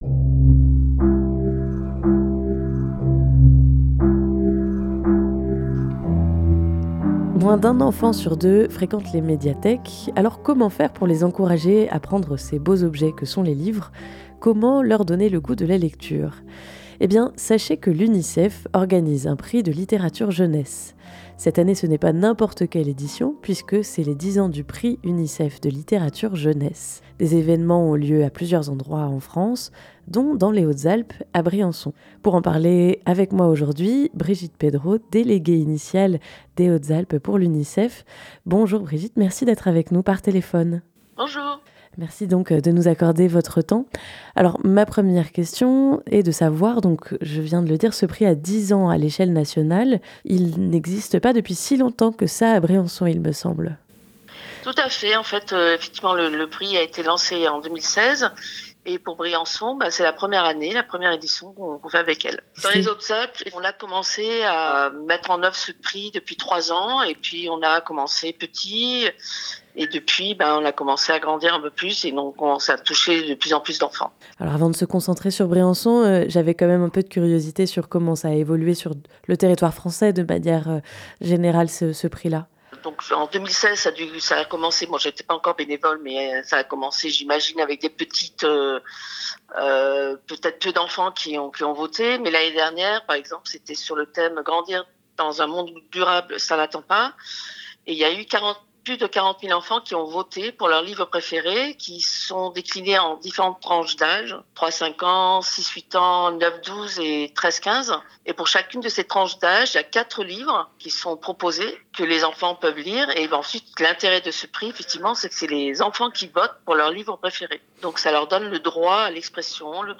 260408 - ITW UNICEF.mp3 (27.47 Mo)